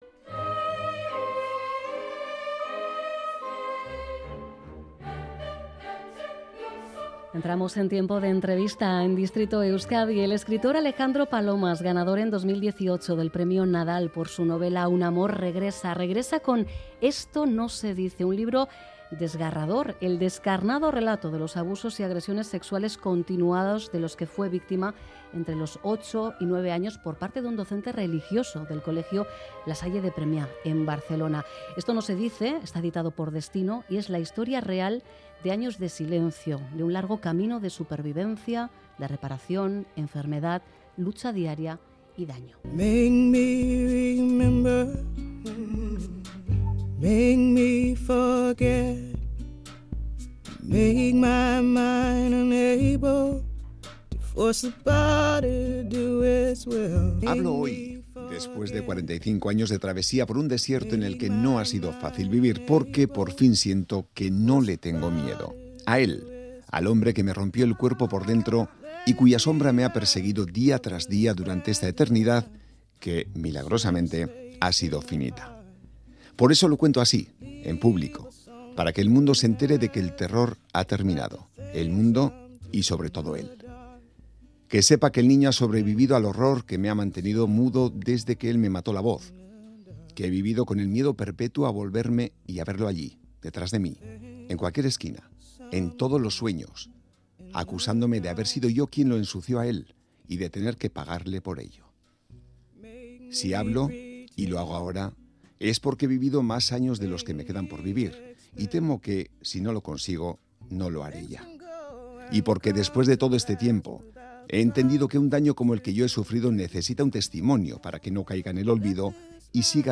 Radio Euskadi ENTREVISTAS